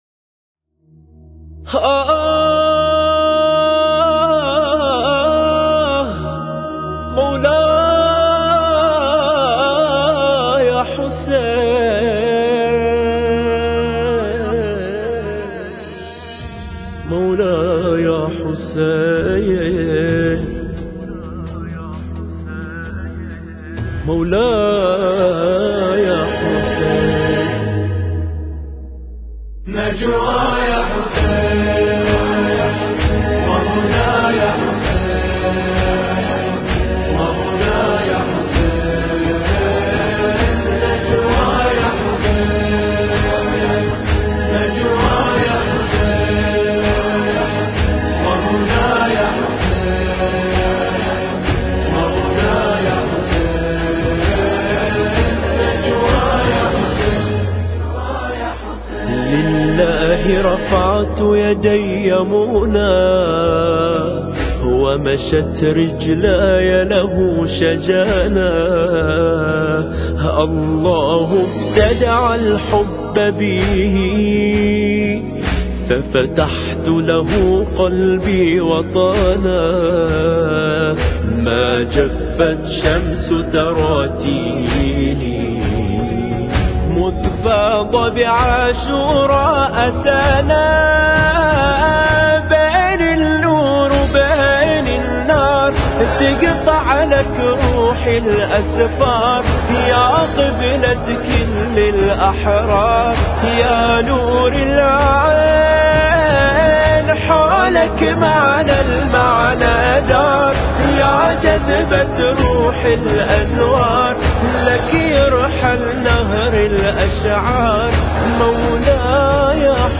المراثي